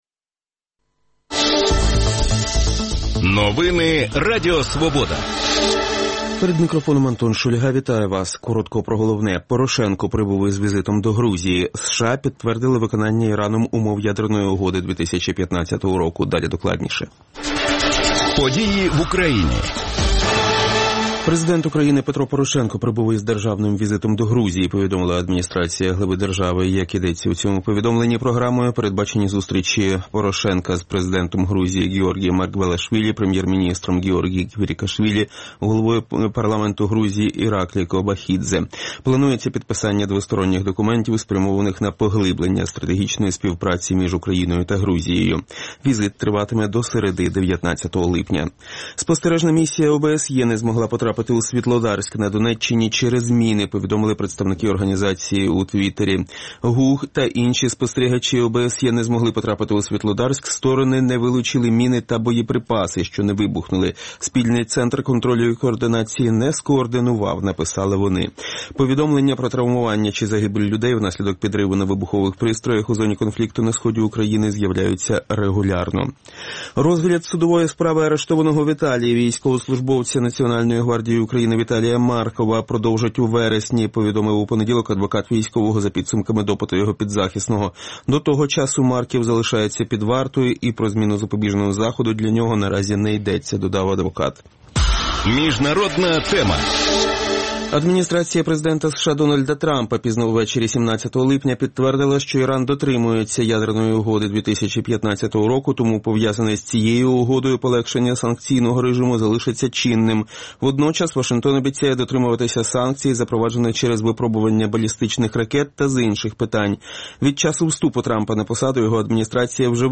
будет говорить с гостями студии